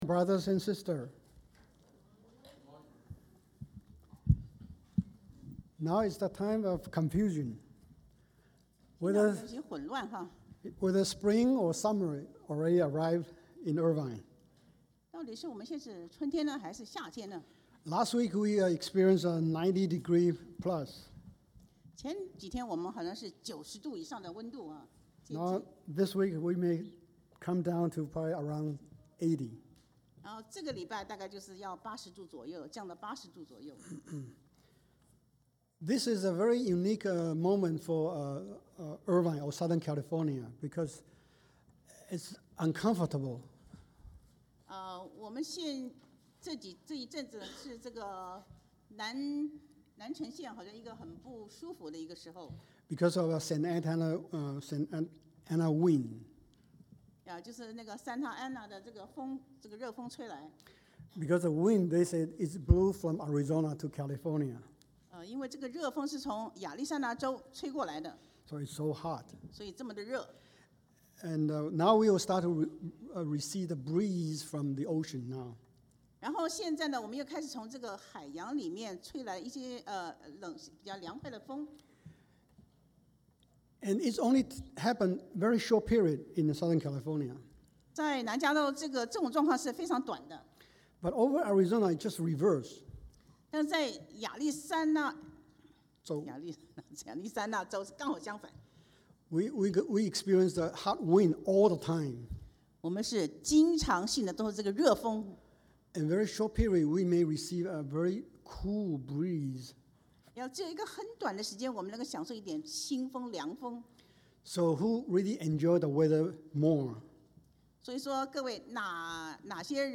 Sermon - CCBCSOC